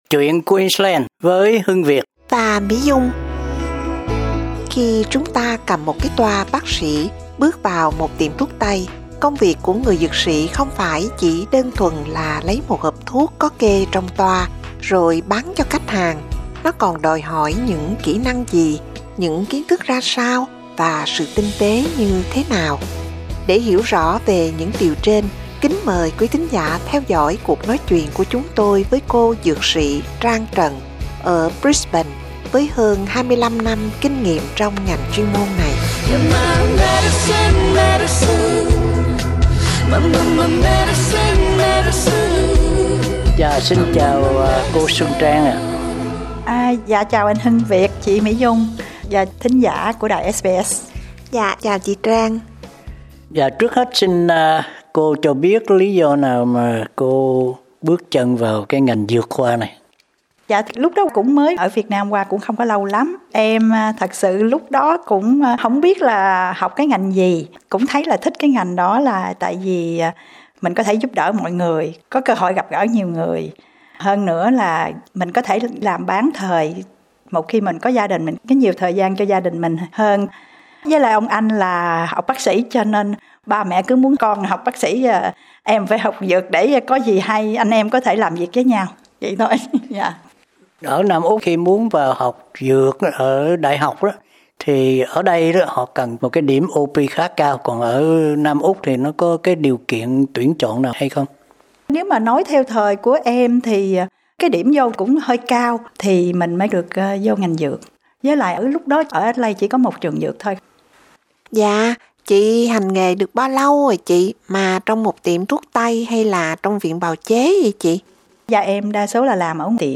cuộc nói chuyện